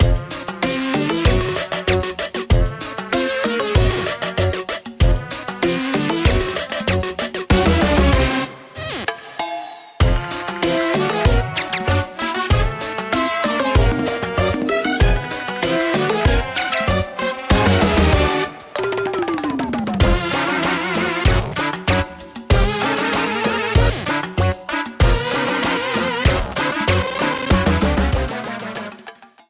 Ripped from the game
faded out the last two seconds